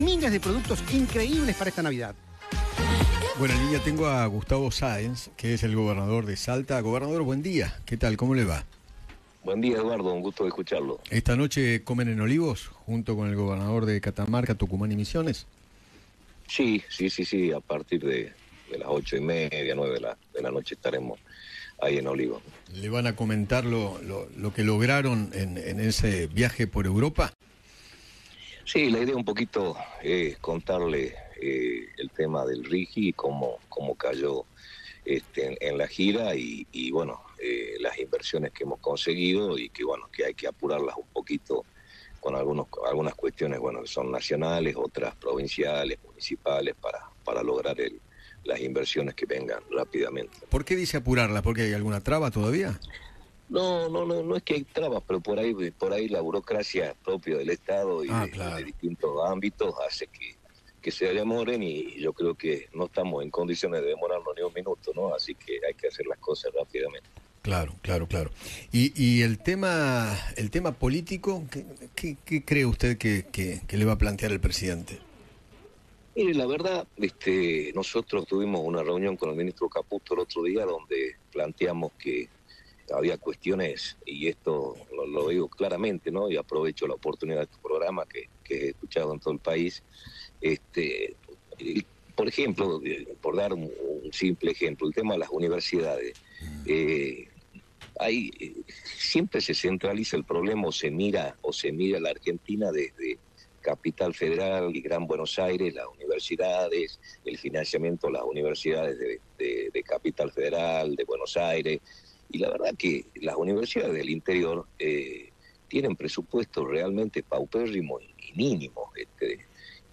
El gobernador de Salta, Gustavo Sáenz, conversó con Eduardo Feinmann sobre la cena que tendrá con Javier Milei junto a otros mandatarios provinciales.